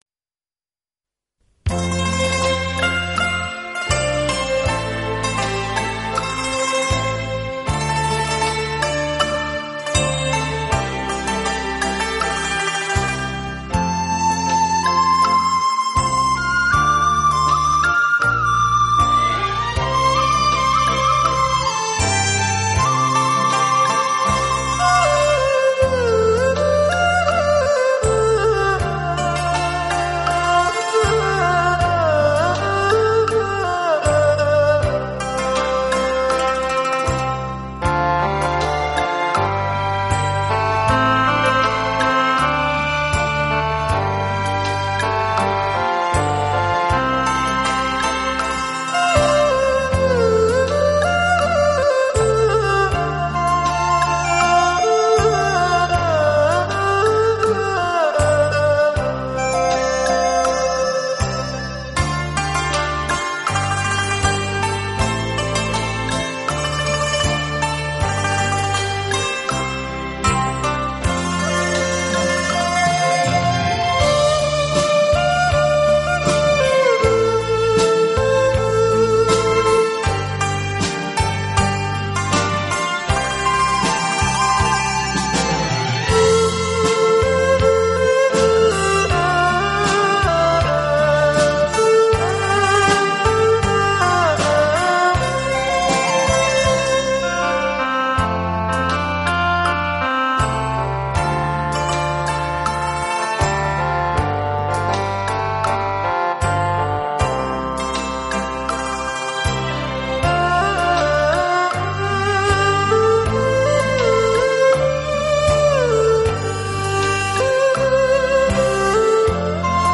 类别: 轻音乐